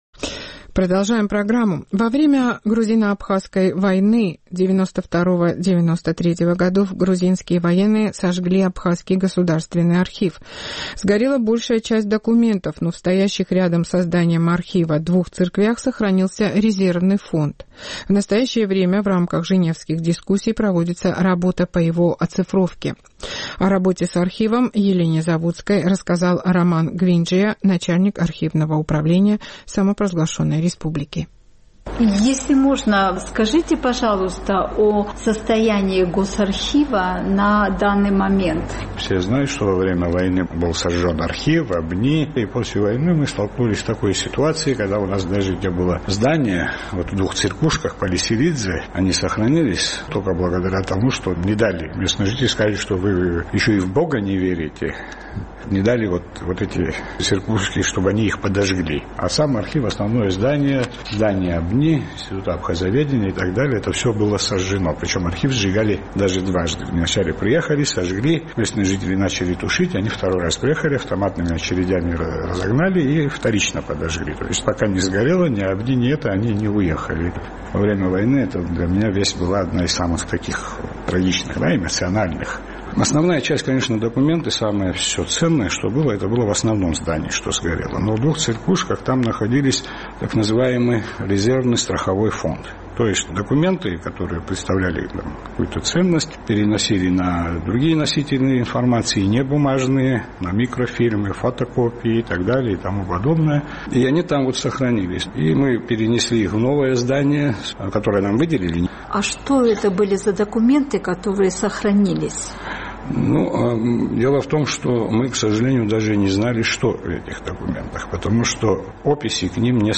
В настоящее время в рамках Женевских дискуссий проводится работа по его оцифровке. О работе с архивом рассказал Роман Гвинджия, начальник Архивного управления Республики Абхазия.